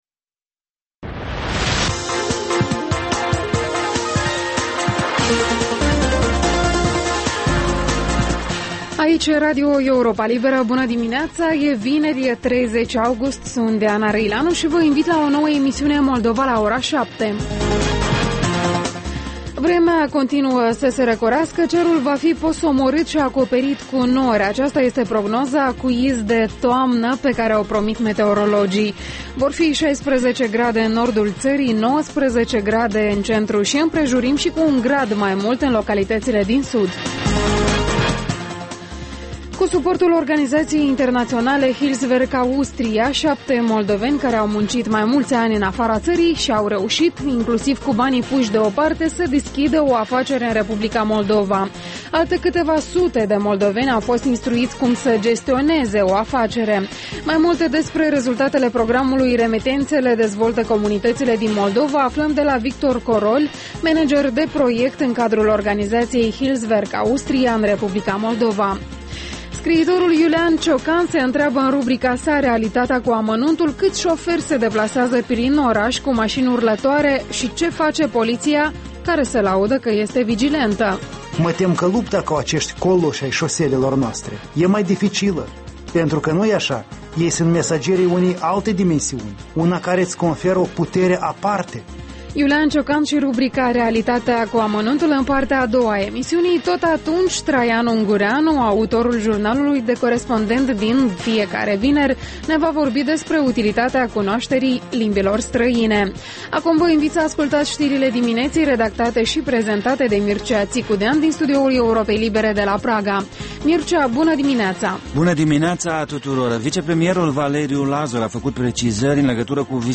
Ştiri, interviuri, analize. Programul care stabileşte agenda zilei.